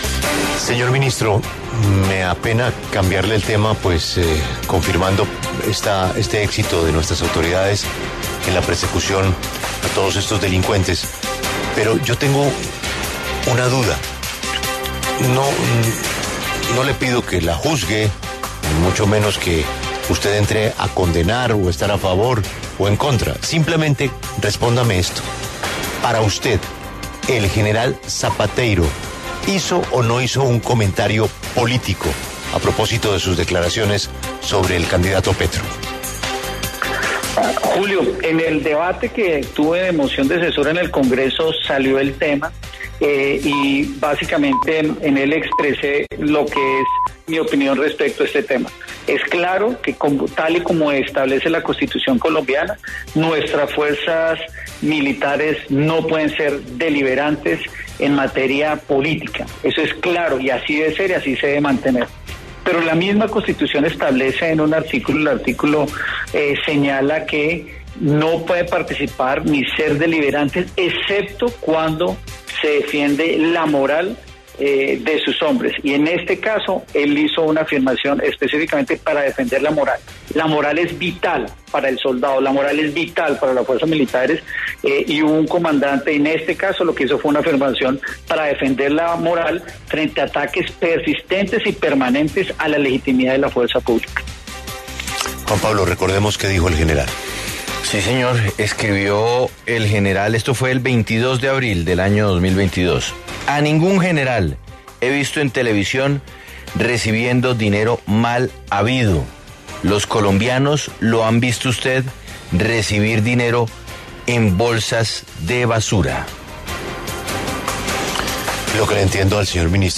El ministro de Defensa, Diego Molano, se pronunció en La W sobre las declaraciones del general Eduardo Zapateiro el pasado 22 de abril.
Lo invitamos a que escuche la entrevista completa al ministro de Defensa, Diego Molano, en La W: